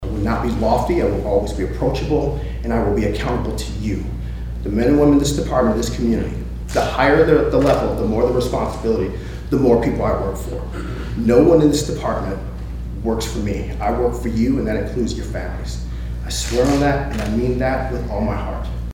Judge Grant Bannister administered the oath of office for newly hired Riley County Police Department Director Brian Peete in the Riley County Courthouse on Friday, December 30, 2022.
Judge Grant Bannister Friday administered the oath of office for new RCPD Director Brian Peete, who remarked on the responsibility of the job and pledged himself to uphold the highest ethical standards in the line of duty.